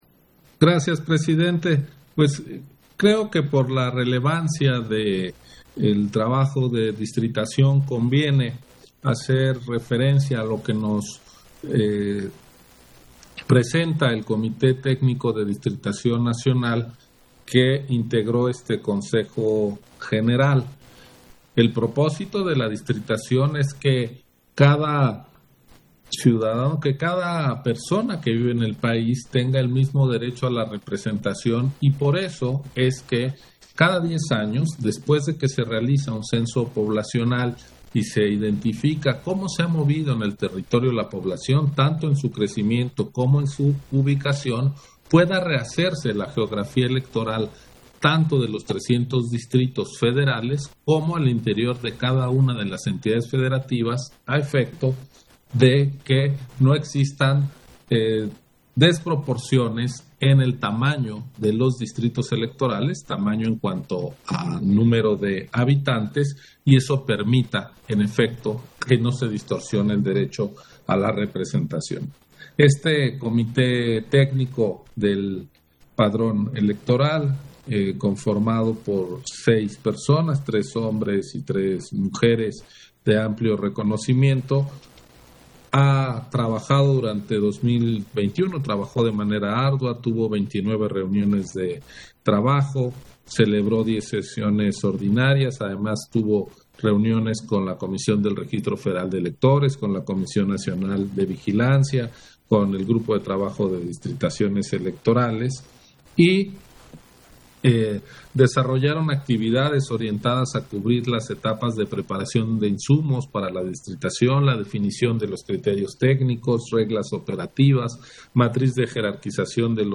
Intervención de Ciro Murayama, en Sesión Extraordinaria, relativo al informe del Comité Técnico para el Seguimiento y evaluación de los trabajos de Distritación Nacional 2021